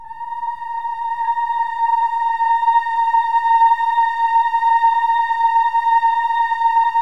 OH-AH  A#5-L.wav